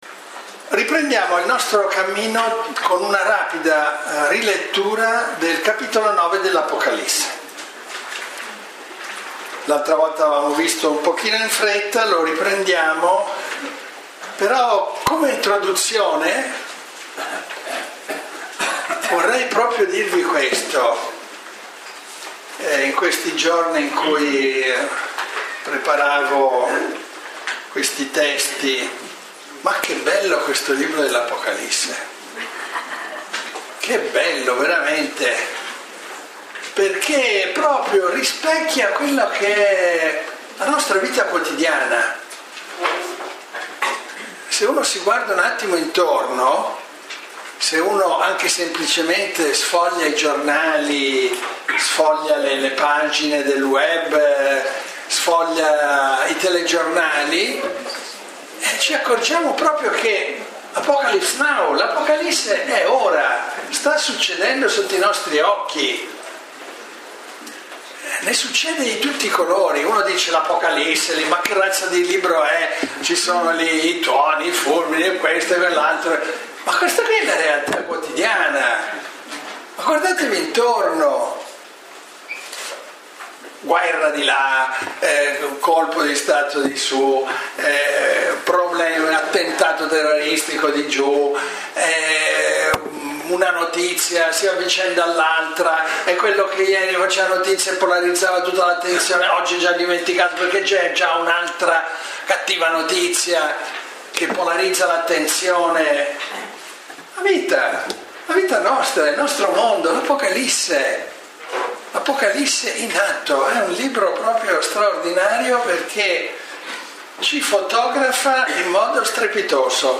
Lectio 4 – 19 gennaio 2014 – Antonianum – Padova